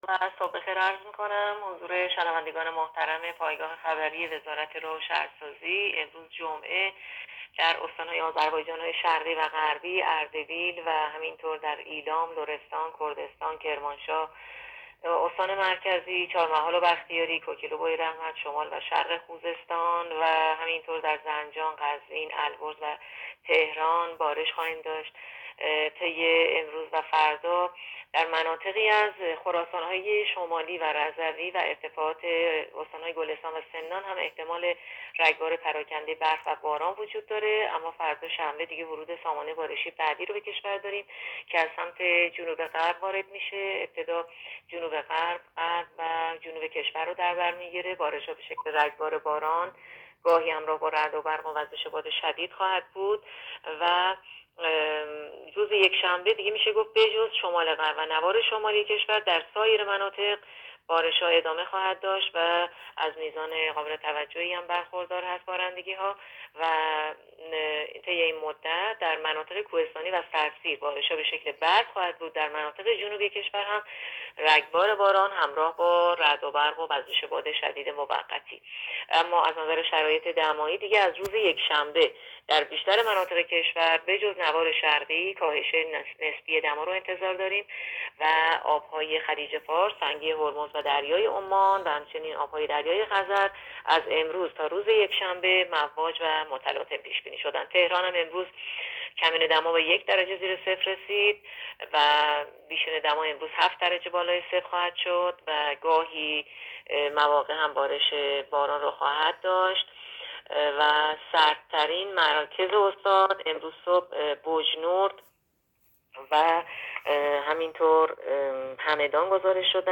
گزارش رادیو اینترنتی از آخرین وضعیت آب و هوای سوم بهمن؛